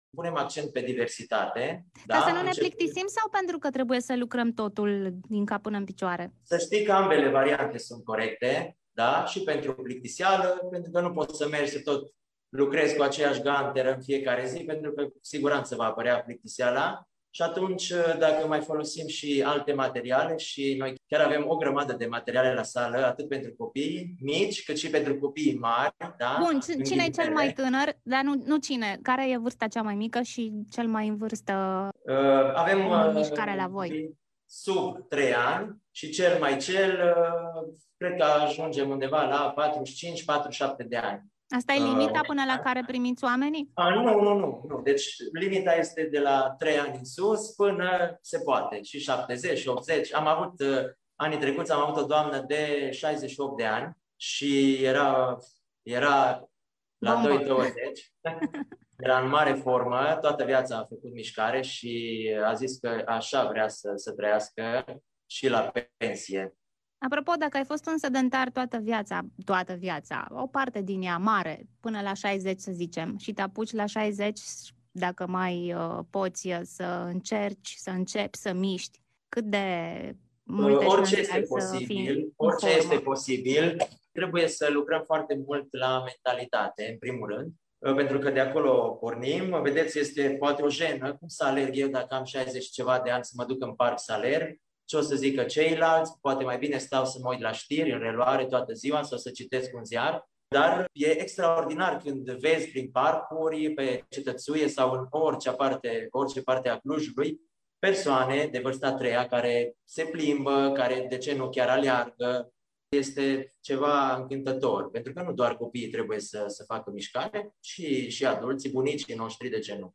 interviu-Minute-de-miscare-fara-intro.mp3